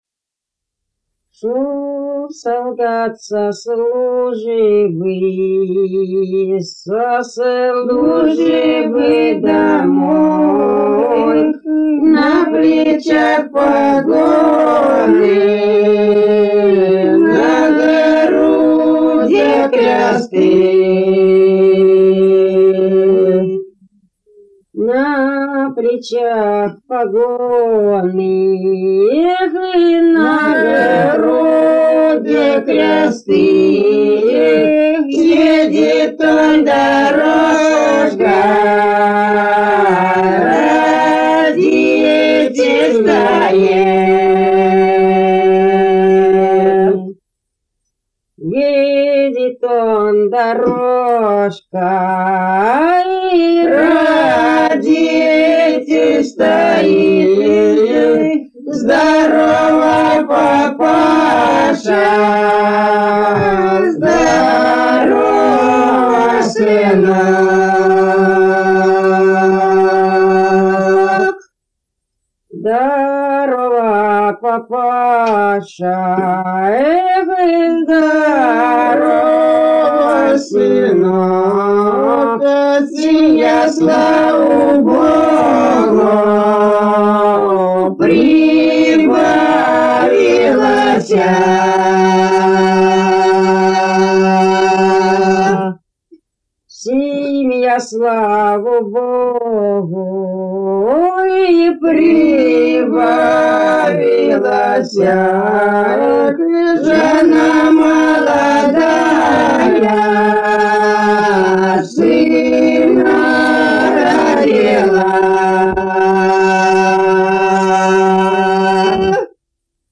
протяжная